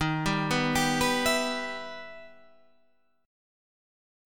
Em/Eb chord